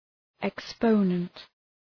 Shkrimi fonetik {ık’spəʋnənt}